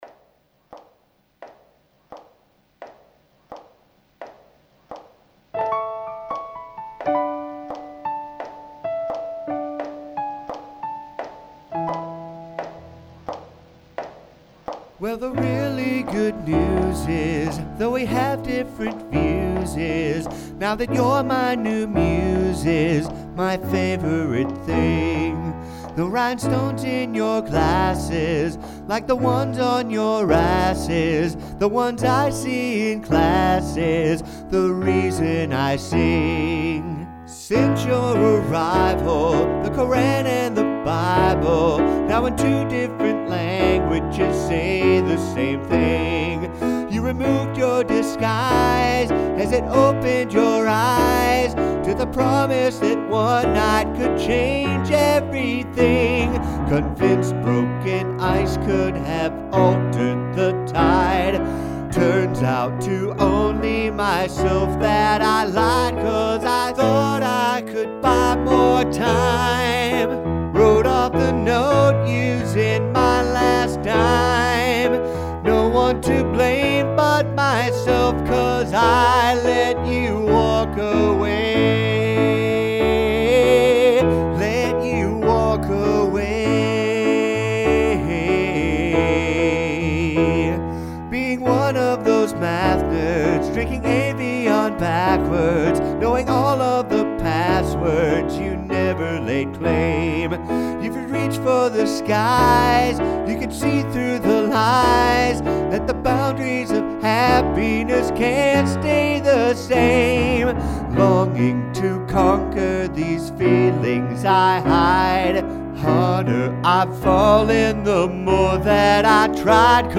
Key of A  –  April, 2008